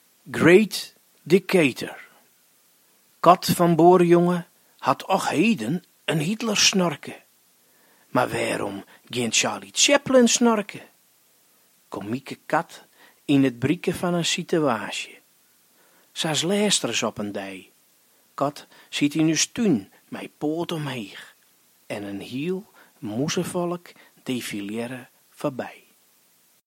draacht syn fers ‘Great Dickator’ foar.